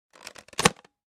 На этой странице вы найдете подборку звуков, связанных с работой фонариков: щелчки кнопок, гудение светодиодов, шум переключателей.
В фонарь вставили батарейки или поставили лампу